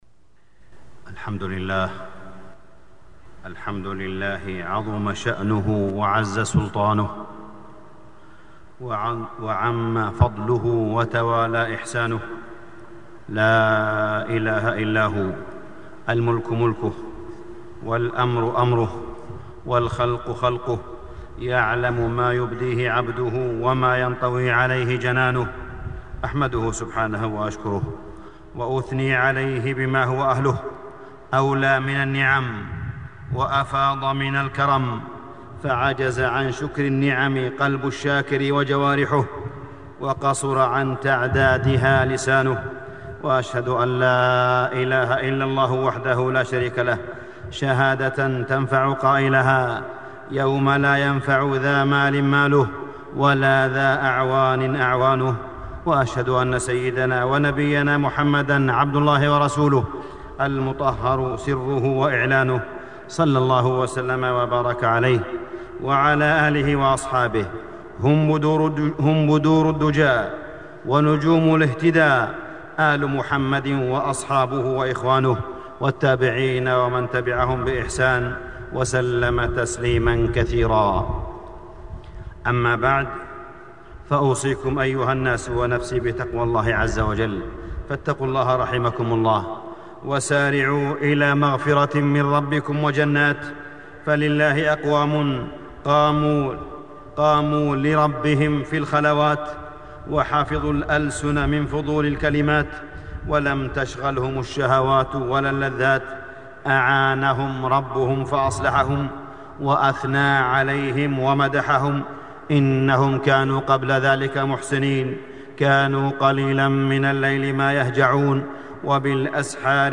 تاريخ النشر ٨ شعبان ١٤٢٧ هـ المكان: المسجد الحرام الشيخ: معالي الشيخ أ.د. صالح بن عبدالله بن حميد معالي الشيخ أ.د. صالح بن عبدالله بن حميد فجر جديد